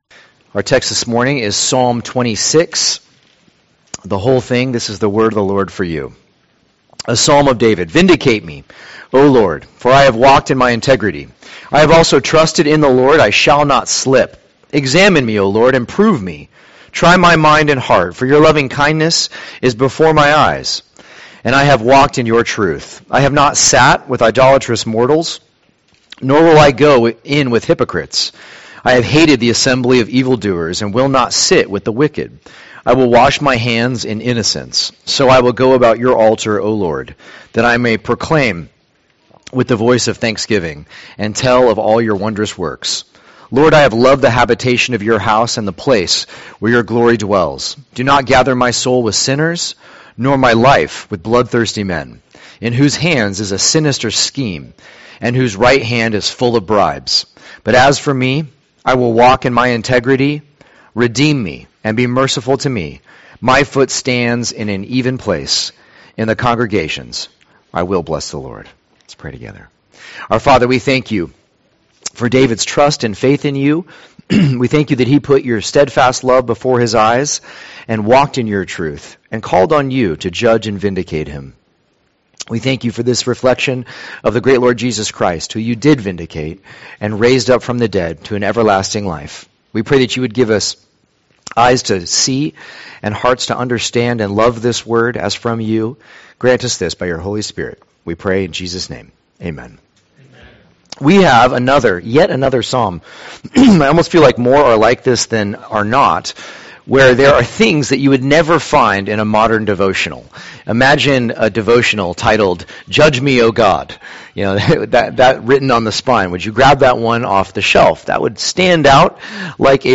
O Lord Preacher